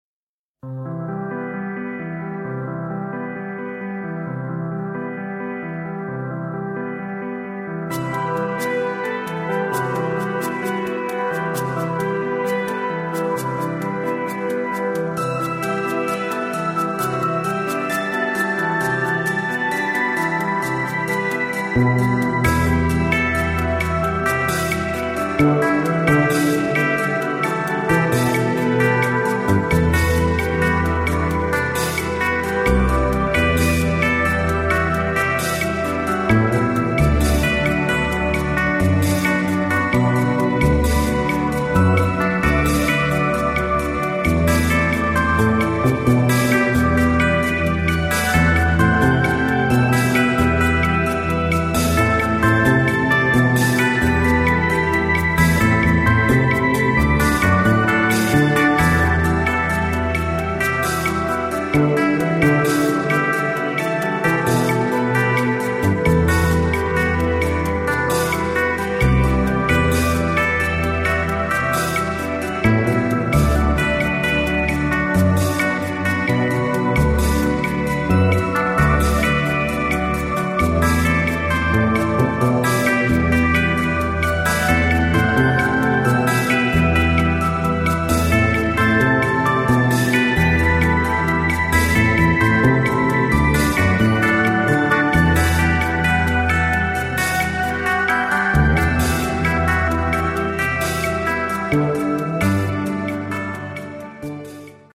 Often ethereal